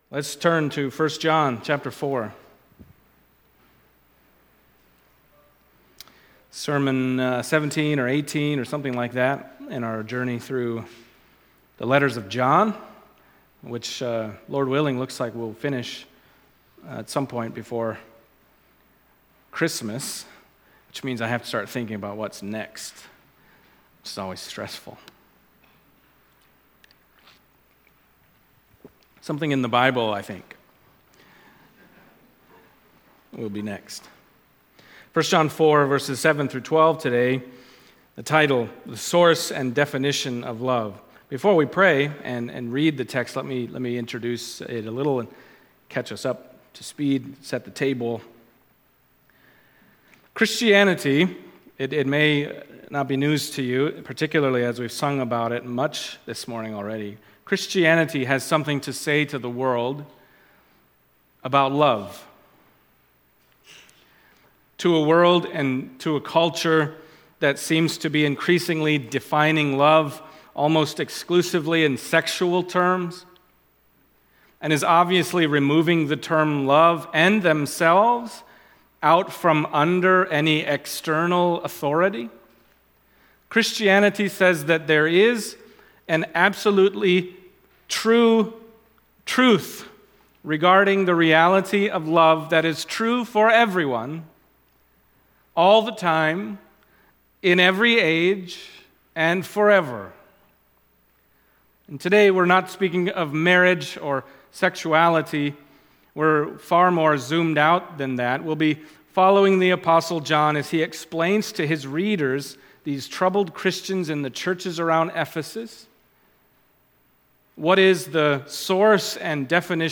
Passage: 1 John 4:7-12 Service Type: Sunday Morning